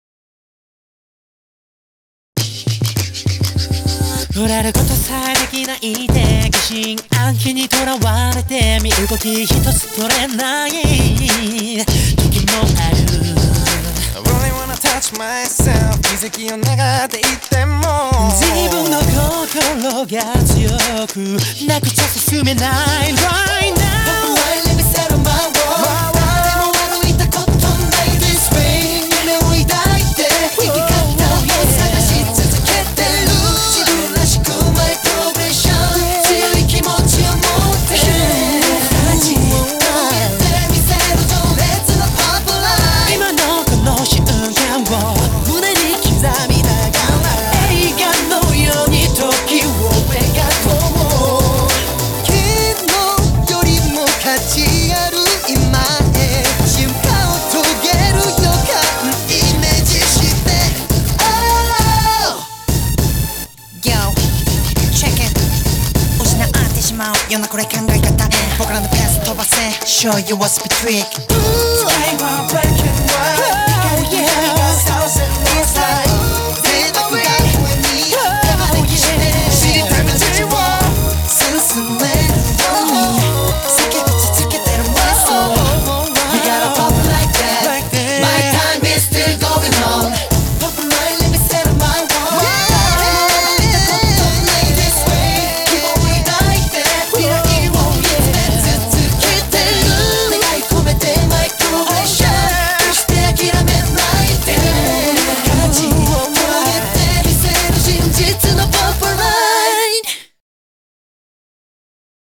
BPM101